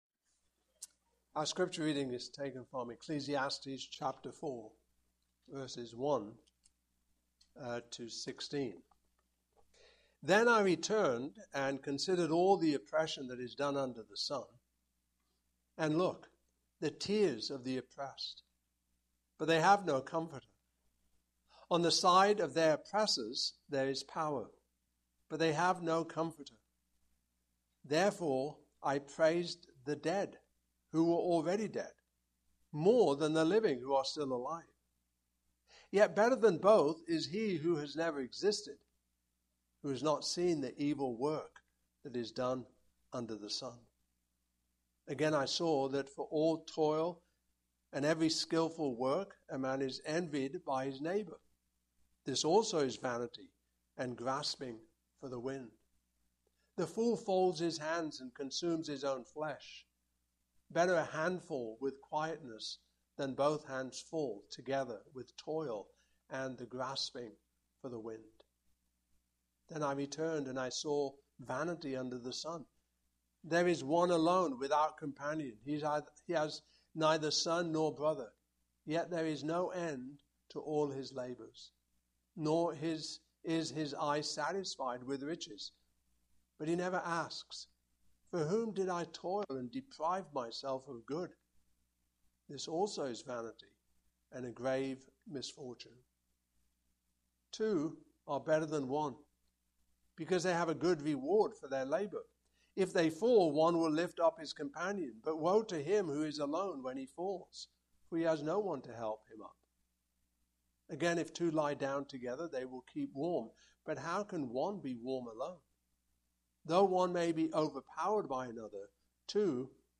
The Book of Ecclesiastes Passage: Ecclesiastes 4:1-16 Service Type: Morning Service « Redemption